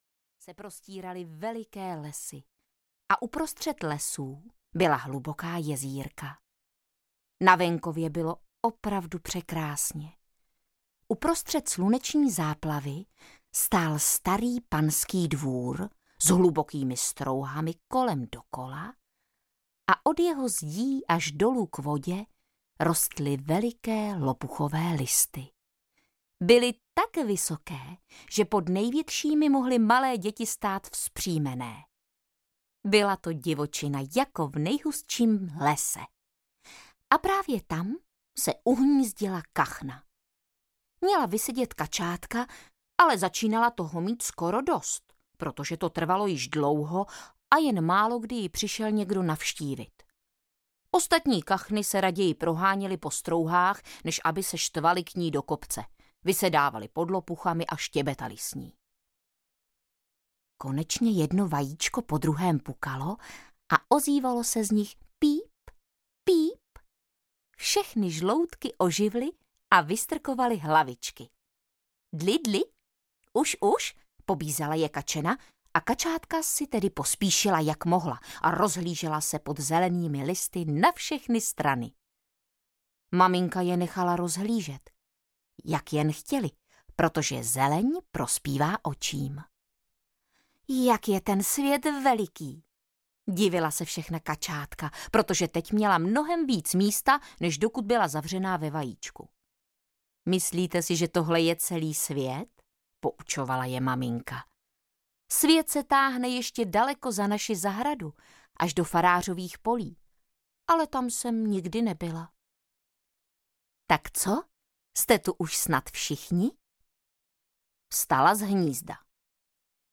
Ošklivé káčátko audiokniha
Ukázka z knihy
• InterpretVáclav Knop